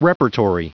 Prononciation du mot repertory en anglais (fichier audio)
Prononciation du mot : repertory